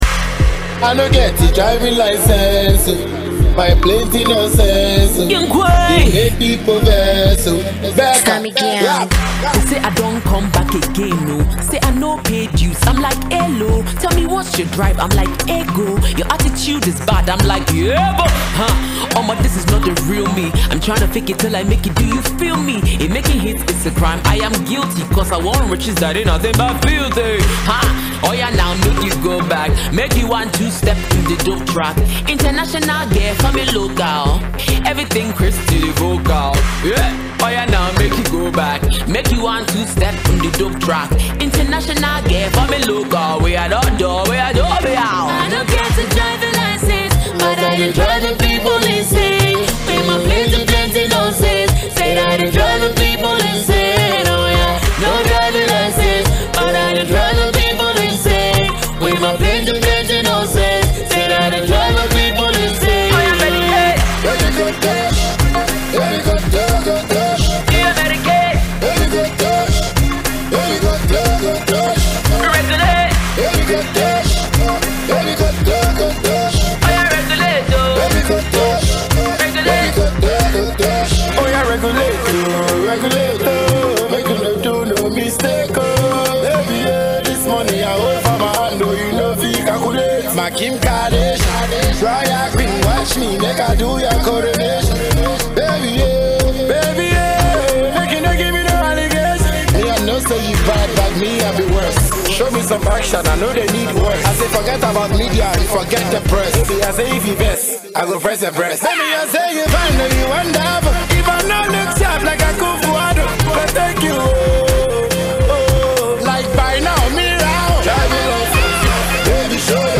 Ghanaian hiplife singer
comes with a Nigerian rhythm